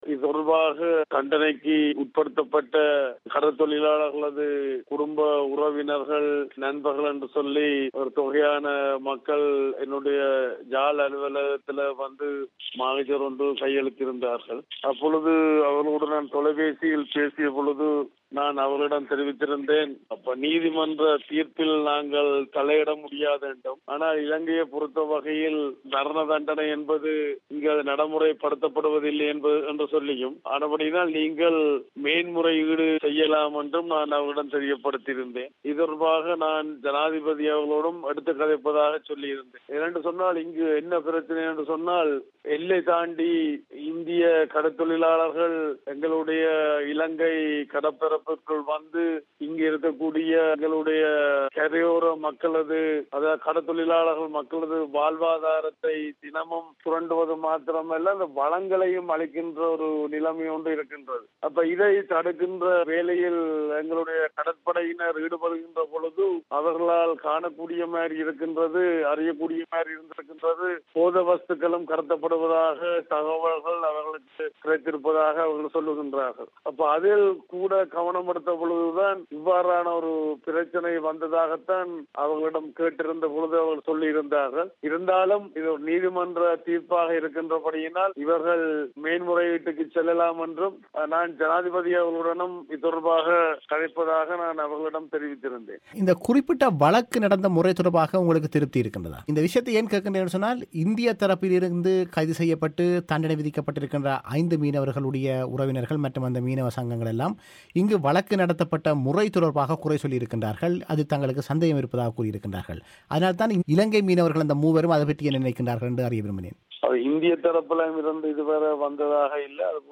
அவரது முழுமையான செவ்வியை நேயர்கள் இங்கு கேட்கலாம்.